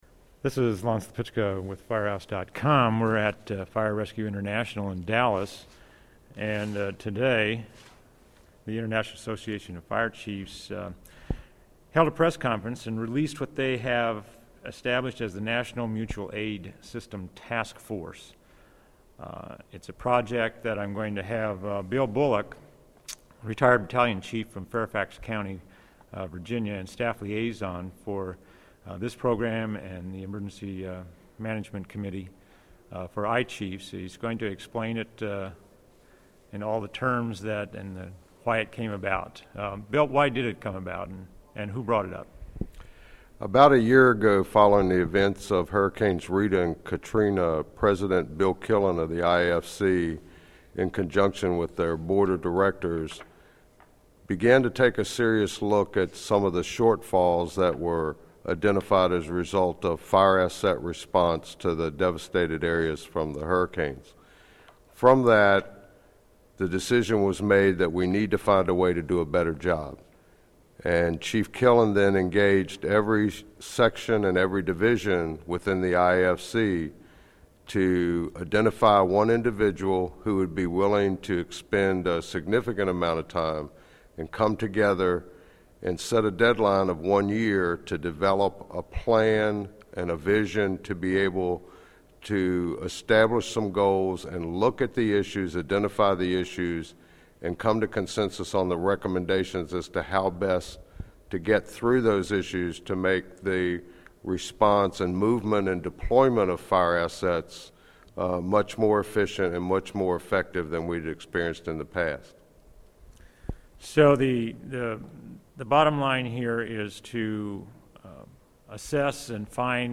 18 Minute Interview